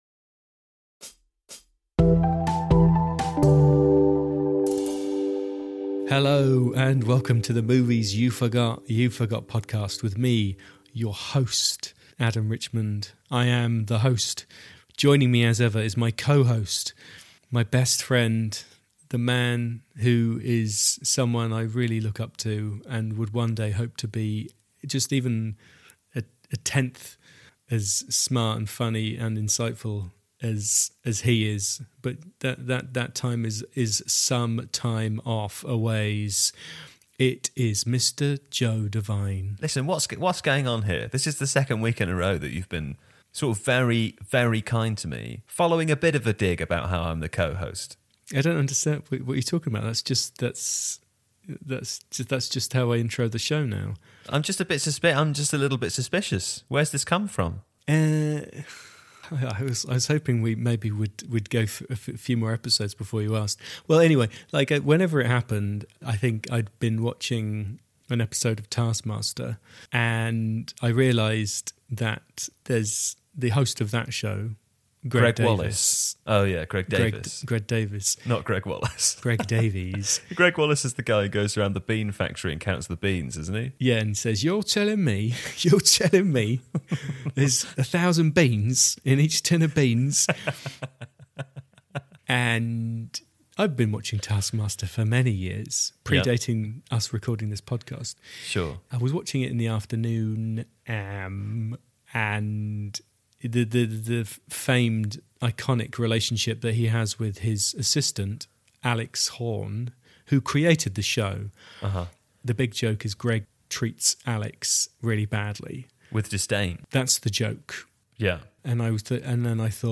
The duo chat violence in movies, post-modernism, what a great film it is, Tarantino’s baffling insistence on appearing in his own films, and getting waylaid by foot massages along the journey.